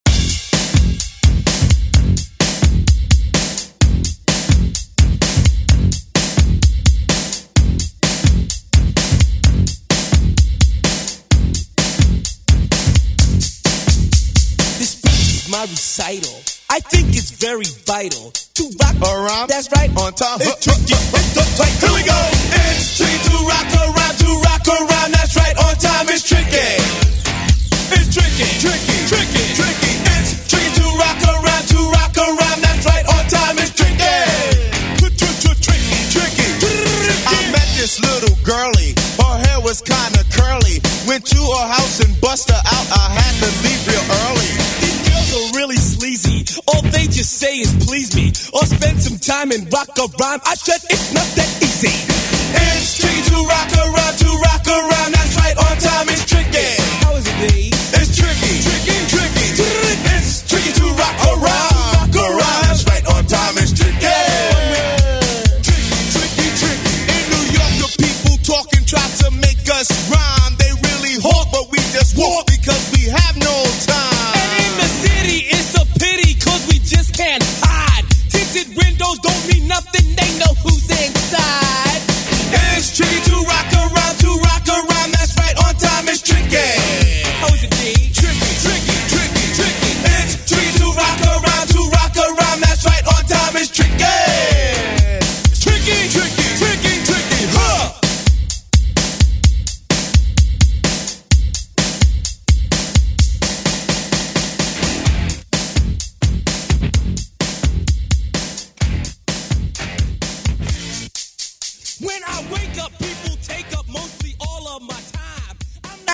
BPM: 128 Time